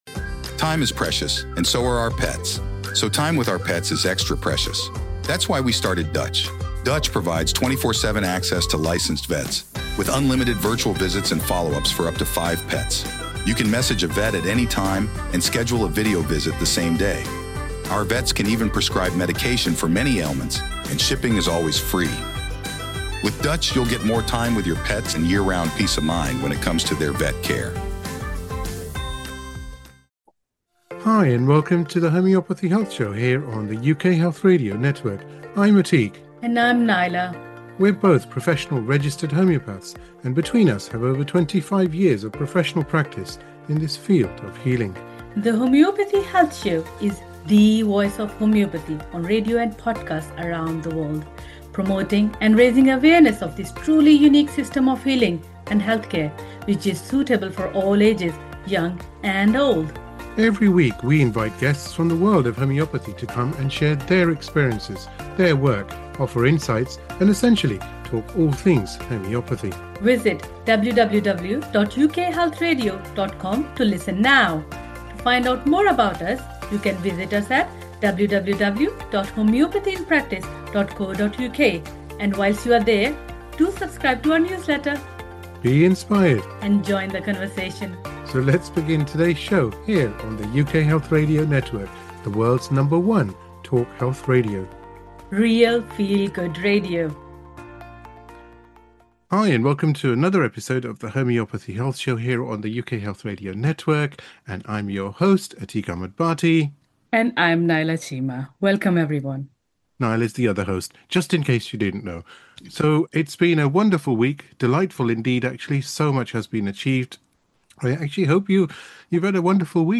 UK Health Radio Podcast